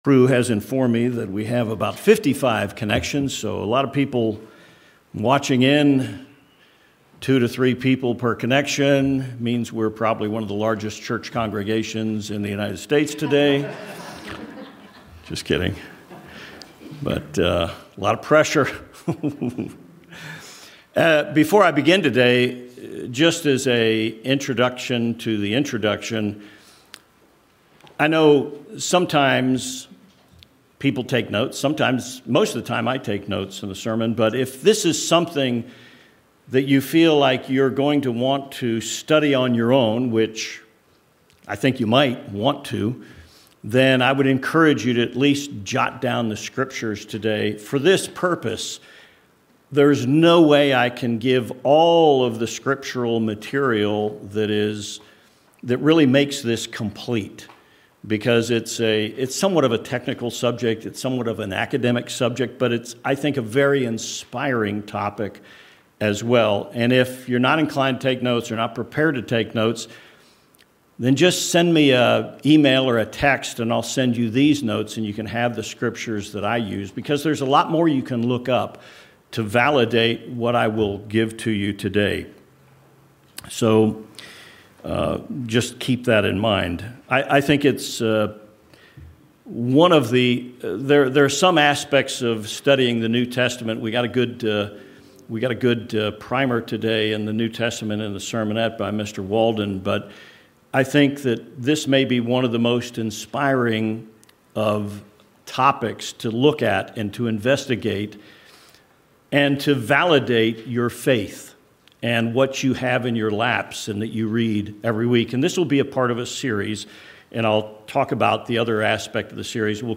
This sermon explains who and when the final inspired scriptures of the NT were established.